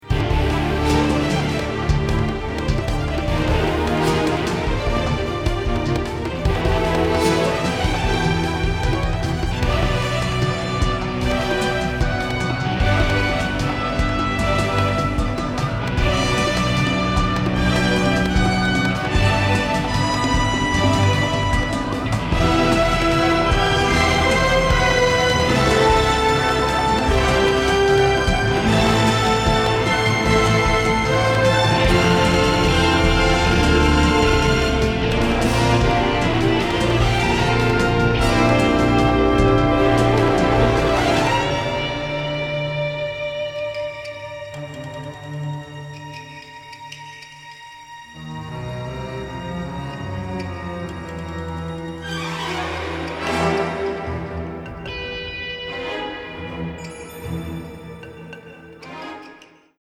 adventure score
Middle Eastern flavor and religious splendor